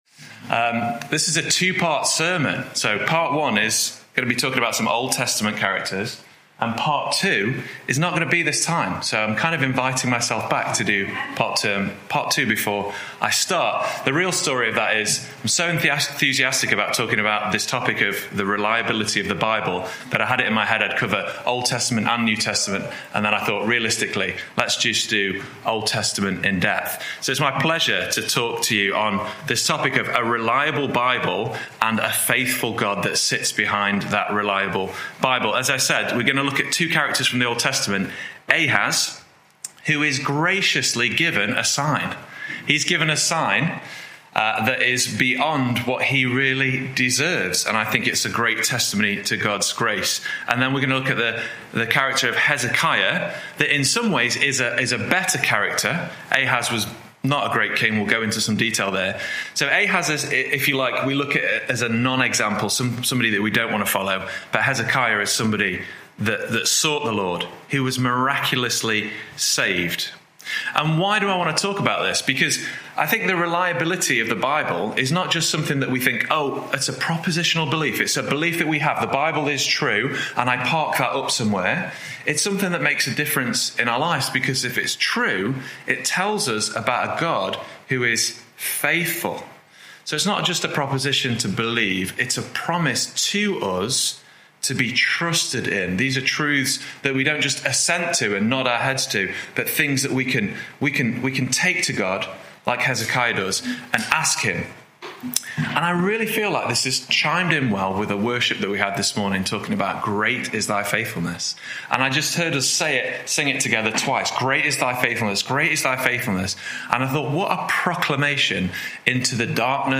Sunday morning studies
Topical Bible studies